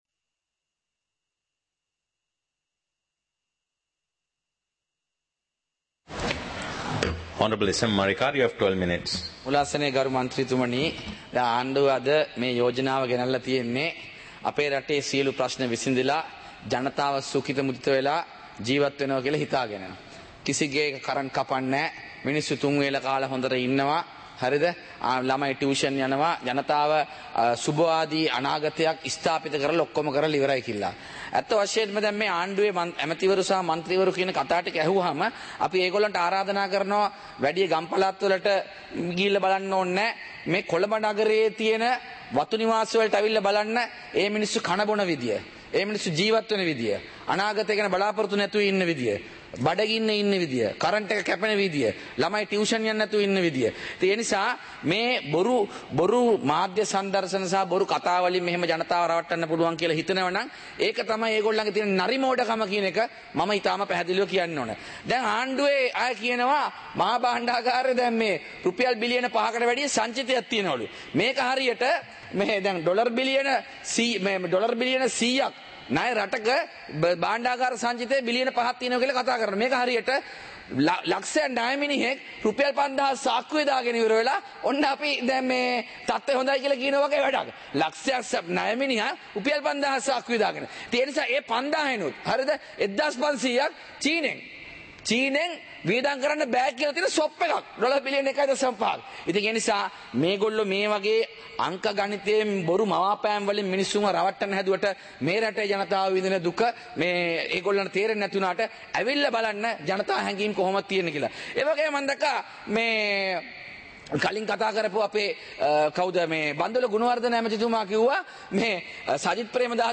சபை நடவடிக்கைமுறை (2024-08-07)
பாராளுமன்ற நடப்பு - பதிவுருத்தப்பட்ட